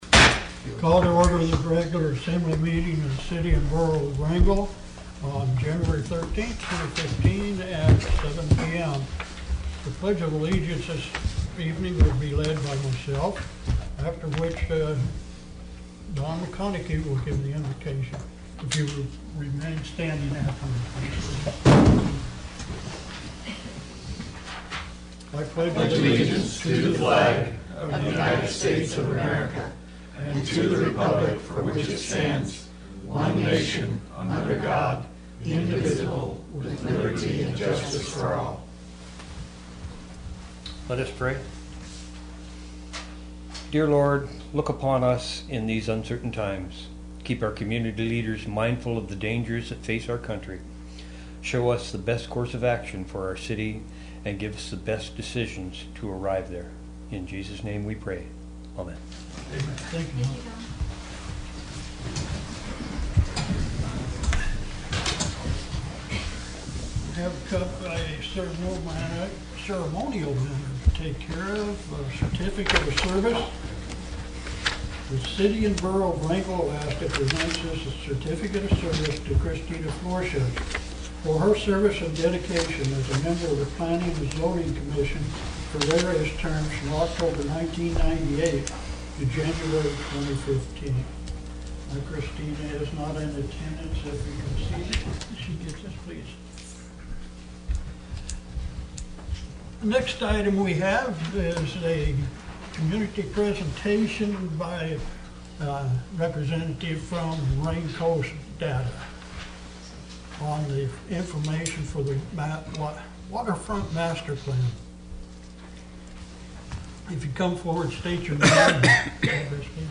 Wrangell's Borough Assembly met for a regular meeting Tuesday, Jan. 13 in the Assembly Chambers.
City and Borough of Wrangell Borough Assembly Meeting AGENDA January 13, 2015 – 7:00 p.m. Location: Assembly Chambers, City Hall